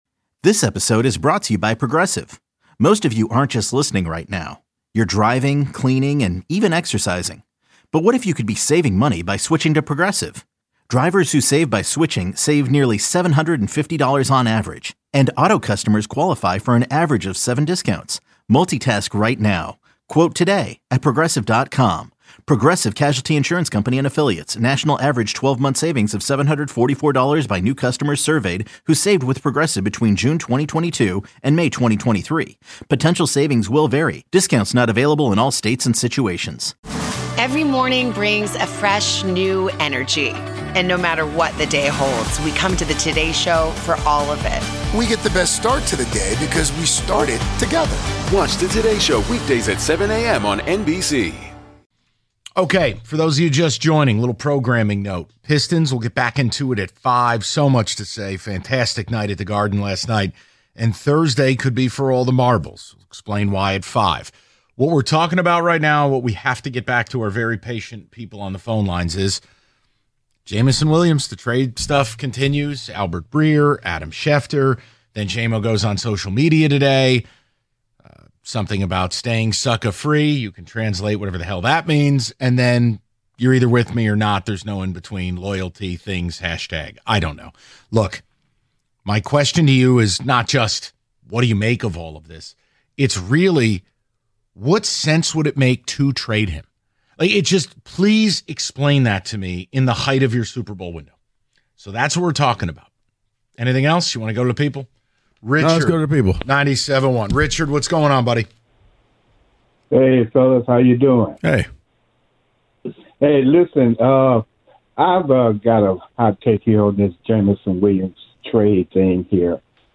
Taking More Of Your Jamo Calls The Valenti Show Audacy Sports 3.8 • 1.1K Ratings 🗓 22 April 2025 ⏱ 11 minutes 🔗 Recording | iTunes | RSS 🧾 Download transcript Summary The guys field more of your calls on the future of Jameson Williams on the Lions.